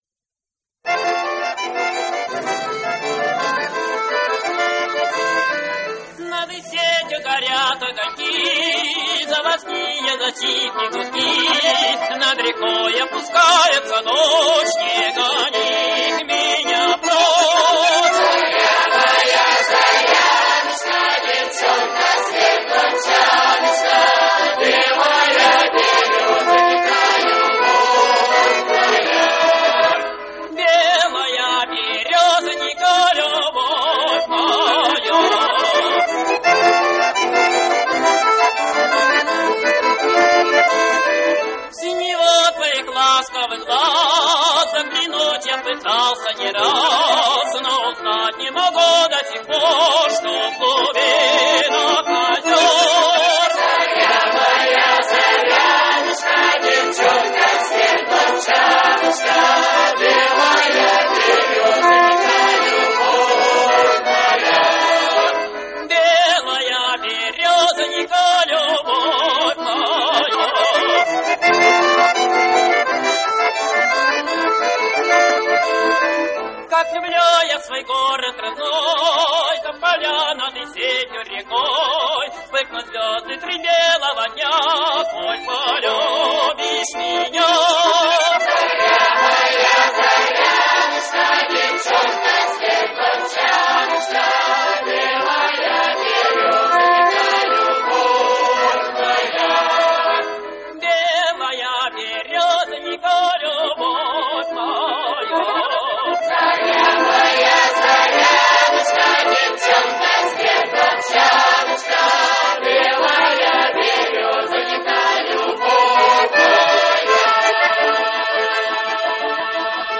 Восстановление нормального звучания (замедление).